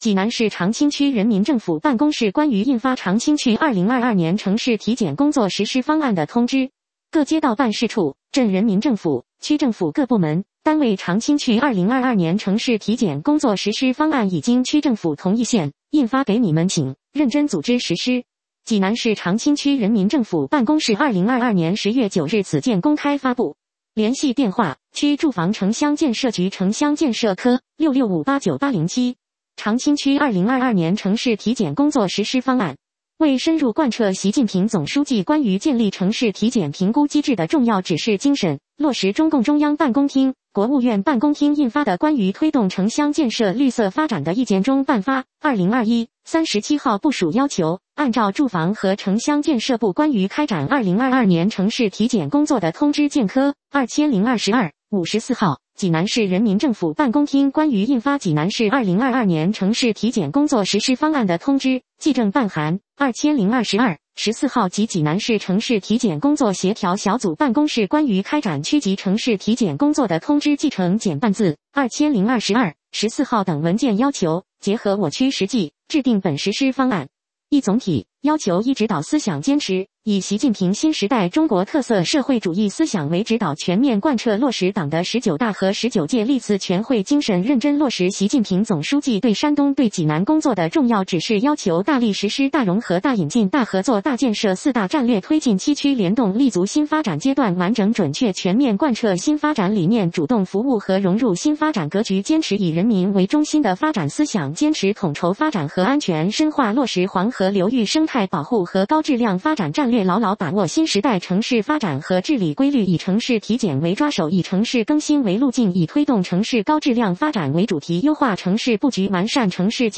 济南市长清区人民政府 - 有声朗读 - 有声朗读：济南市长清区人民政府办公室关于印发长清区2022年城市体检工作实施方案的通知